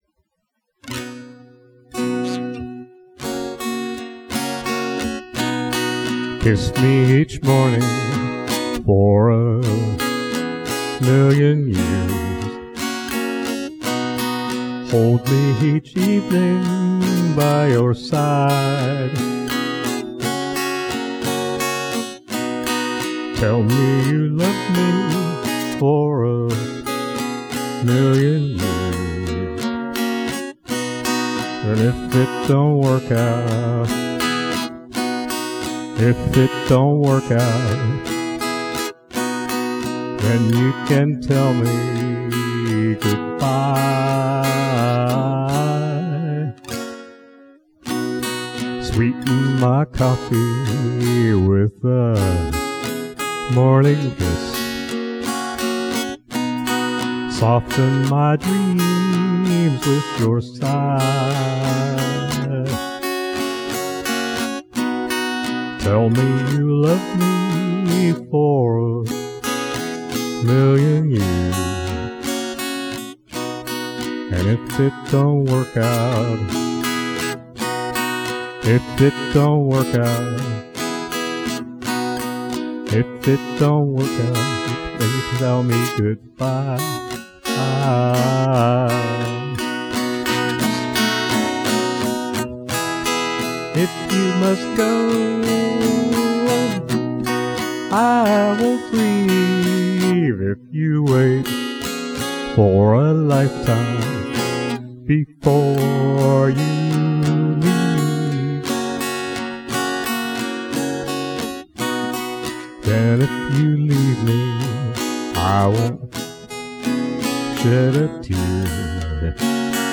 Roughs - sorry - except where you see "credits".